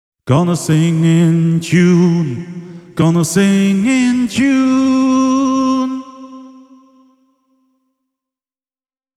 Samankaltainen pätkä, mutta vireenkorjaus on säädetty kello kahteen: